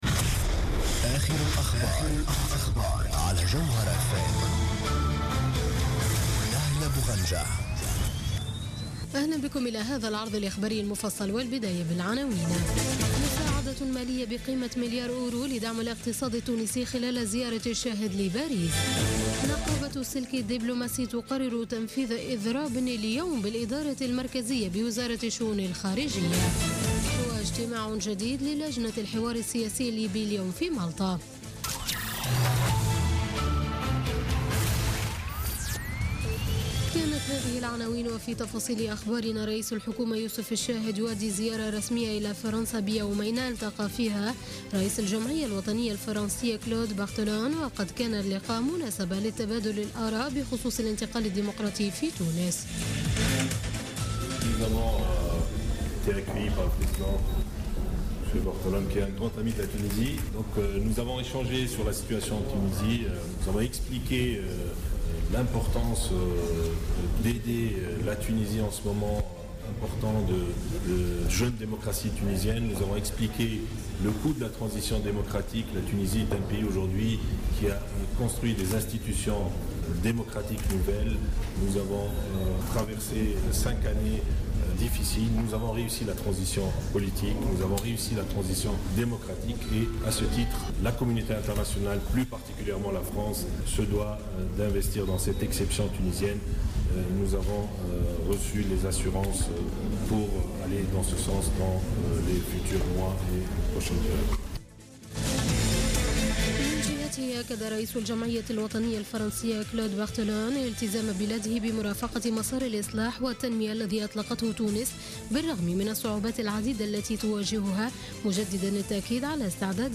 نشرة أخبار منتصف الليل ليوم الخميس 10 نوفمبر 2016